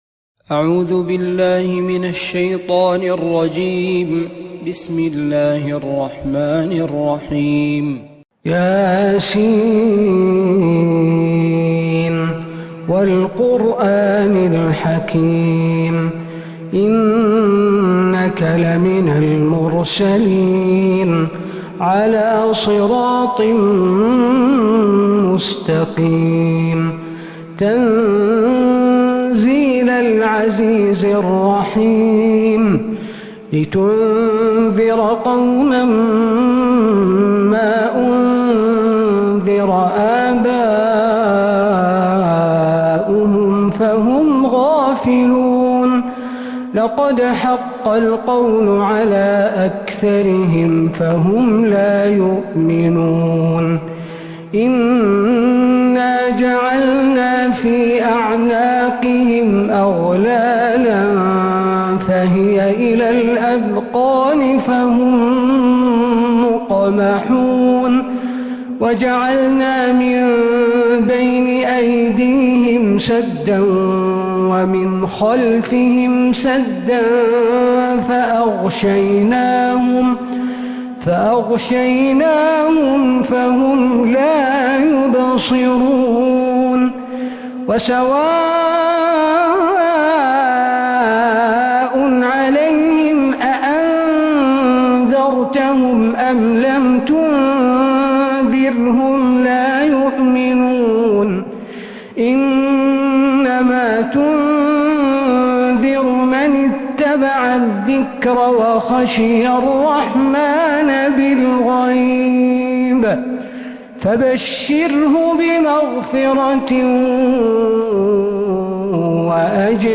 سورة يس كاملة من الحرم النبوي